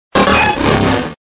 Fichier:Cri 0310 DP.ogg